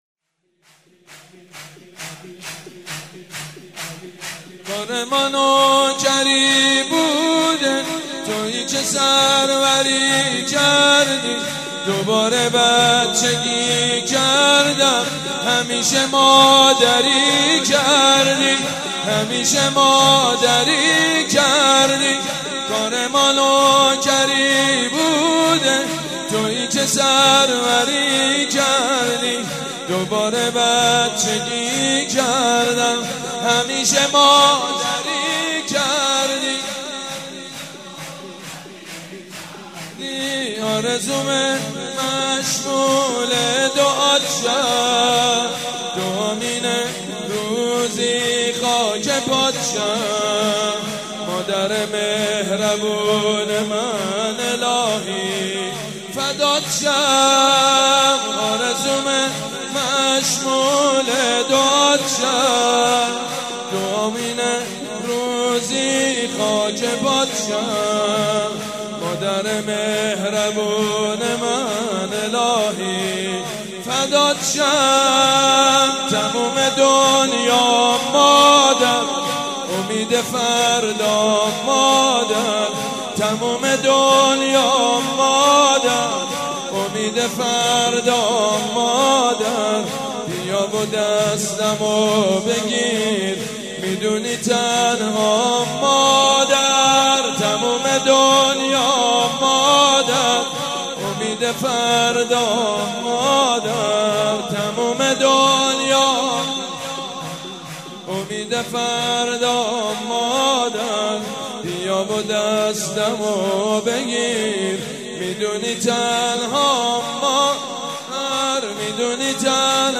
جشن میلادحضرت فاطمۀزهراء/ریحانه الحسین
با مدیحه سرایی